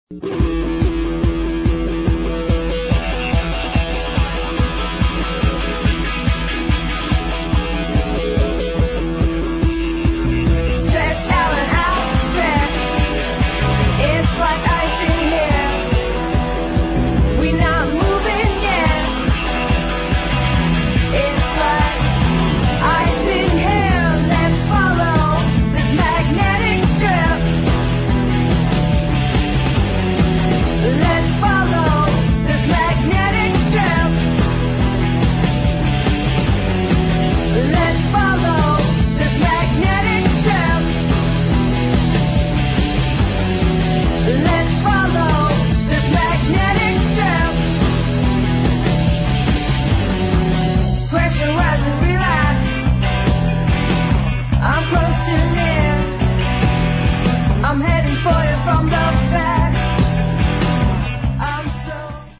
[ ROCK ]